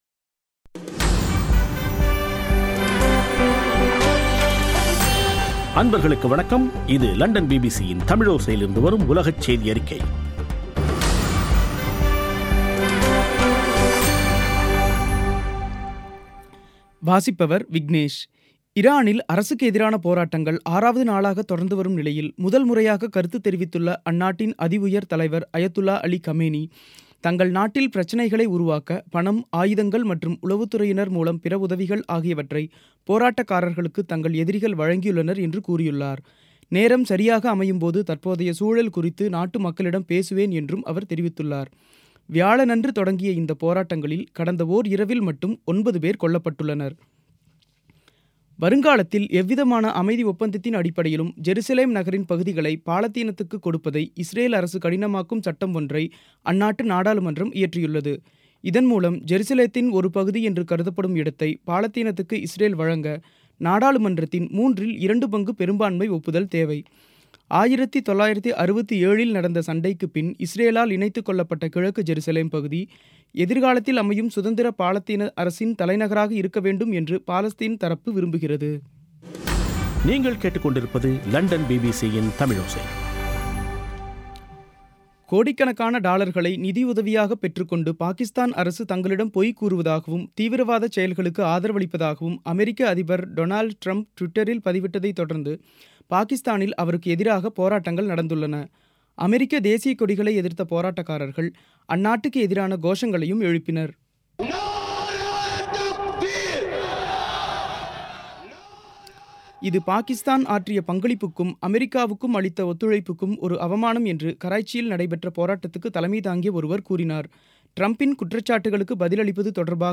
பிபிசி தமிழோசை செய்தியறிக்கை (02/01/2018)